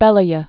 (bĕlə-yə)